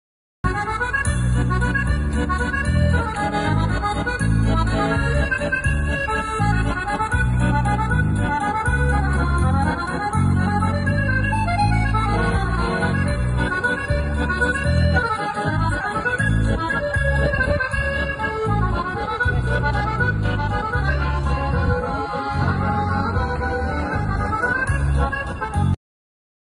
Кисловодск. Курортный бульвар. Уличные музыканты, как воздух апреля 2013.
ulichnyiy-muzyikant.mp3